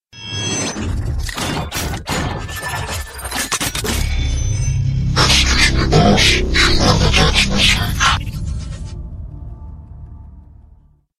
Прикольная мелодия на смс